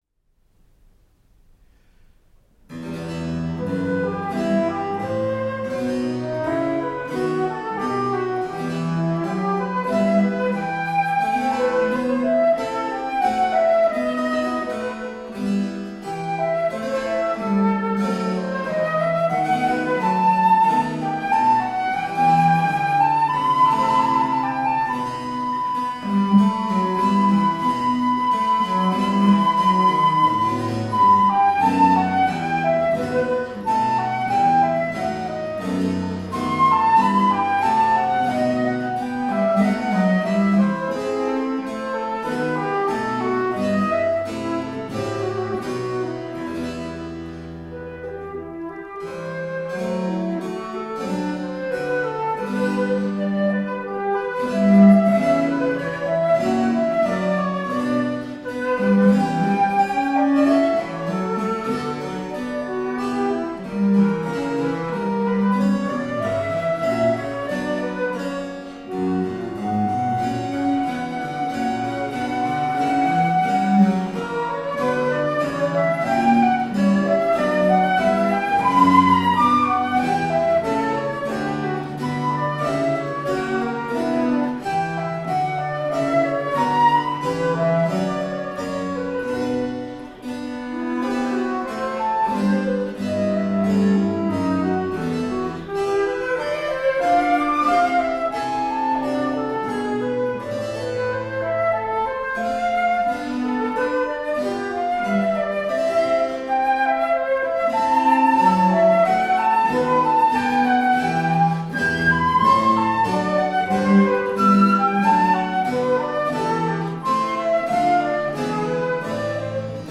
Flautists with finesse, intelligence and grooves.
recorded at the Hakodate City Community Centre 2011.
Classical, Chamber Music, Baroque, Instrumental